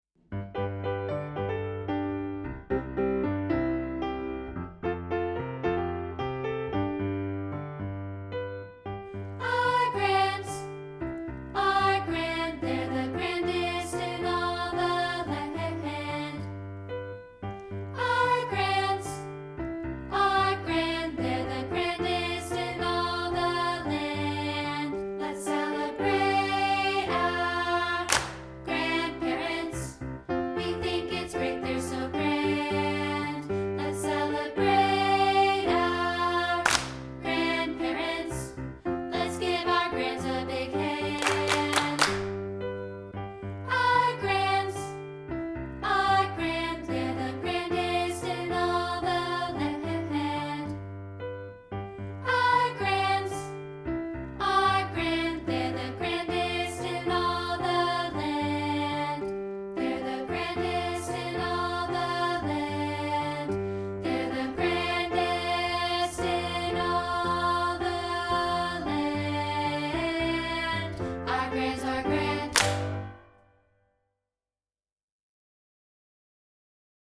including this rehearsal track of part 2, isolated.